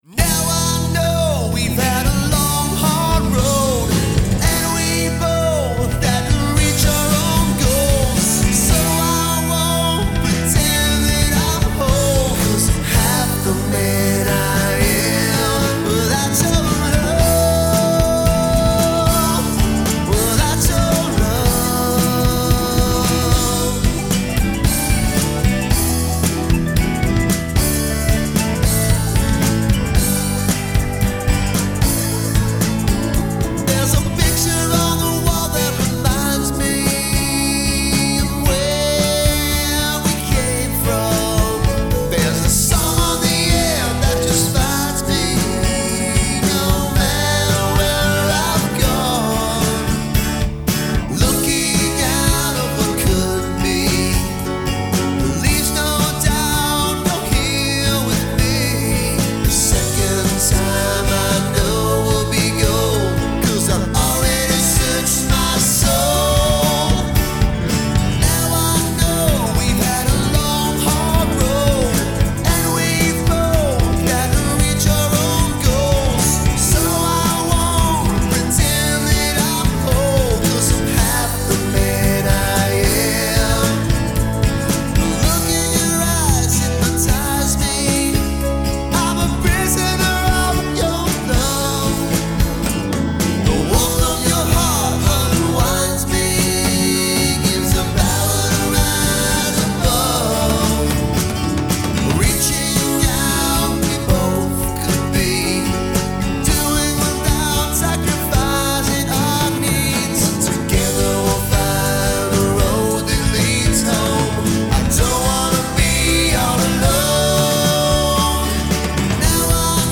music_smorgasbord_withoutyourlove_male.mp3